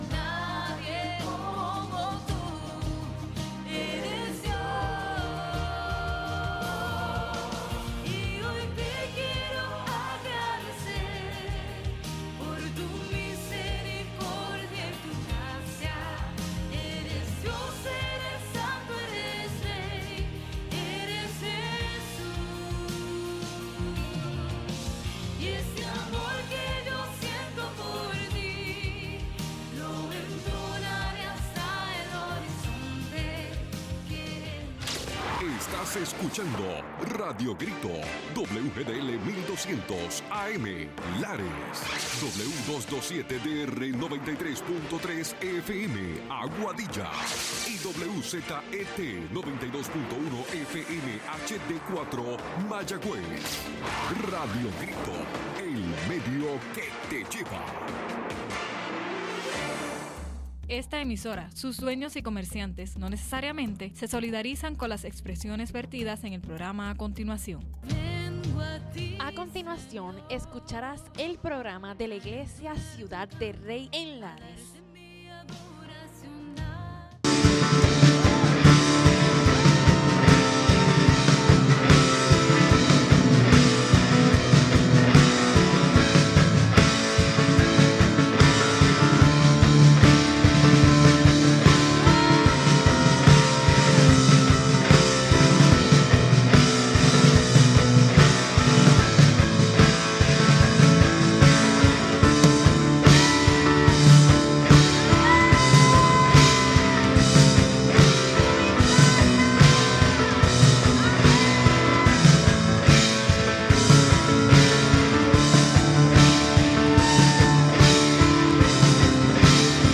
Los hermanos de Ciudad del Rey nos traen un programa especial de su servicio en la iglesia.